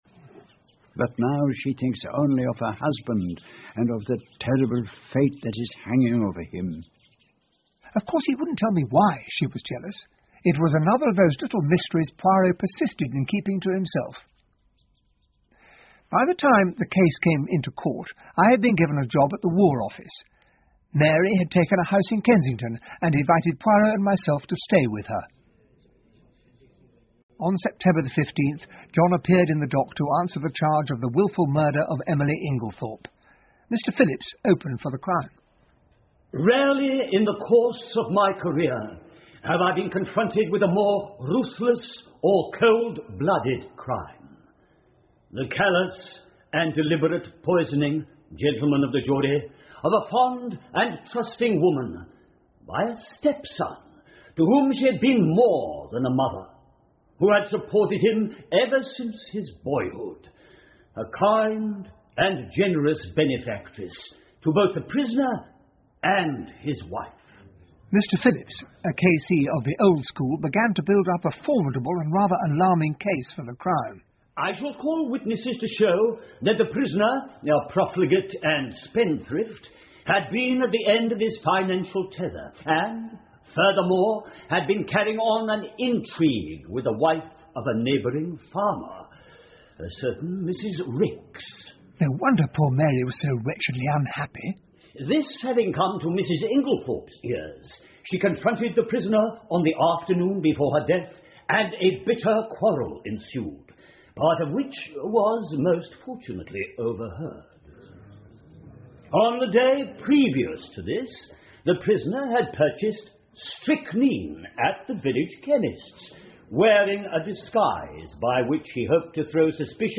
英文广播剧在线听 Agatha Christie - Mysterious Affair at Styles 19 听力文件下载—在线英语听力室